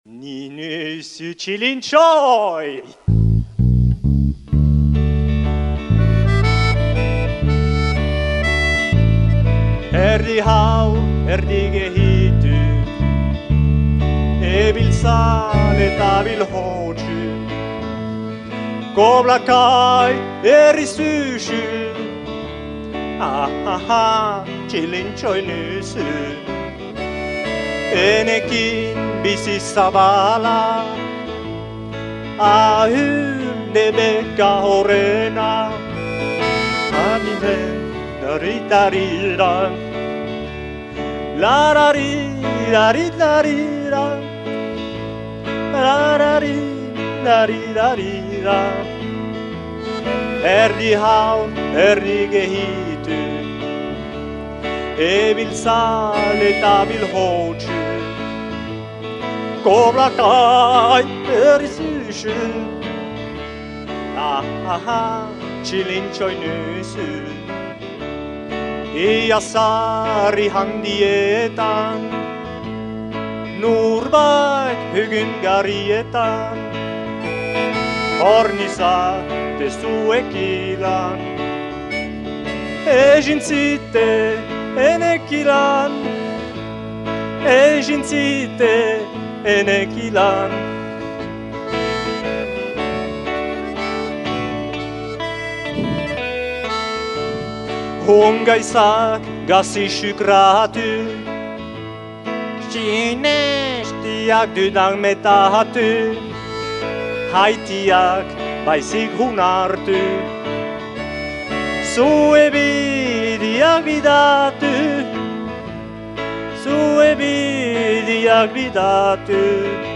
erreportaia